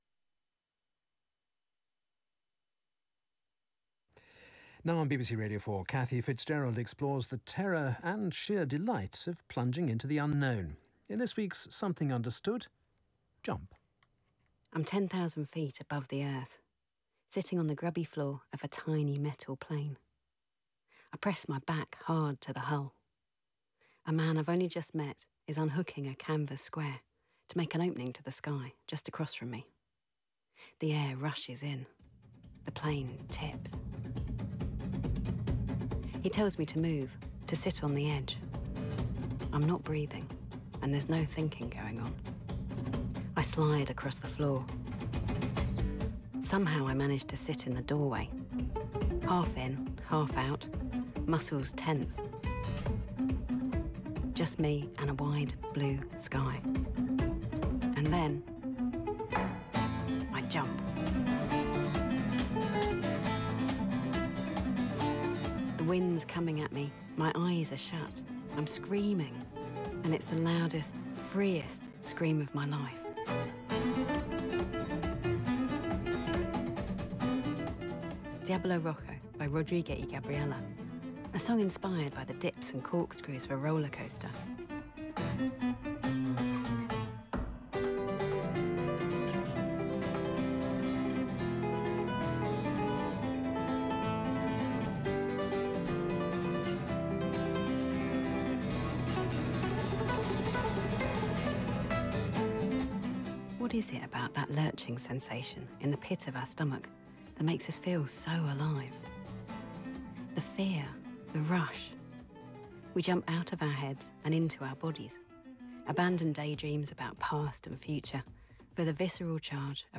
The oscillation between making leaps and dreading them shaped a beautifully produced programme that sounded delicate, but had deep ideas swirling through it.